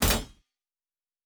pgs/Assets/Audio/Fantasy Interface Sounds/Weapon UI 03.wav at master
Weapon UI 03.wav